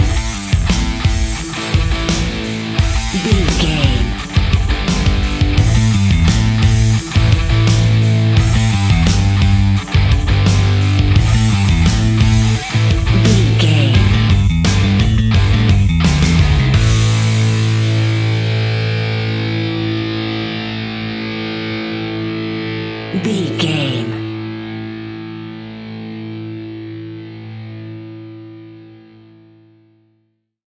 Epic / Action
Fast paced
Aeolian/Minor
hard rock
blues rock
distortion
instrumentals
rock guitars
Rock Bass
Rock Drums
heavy drums
distorted guitars
hammond organ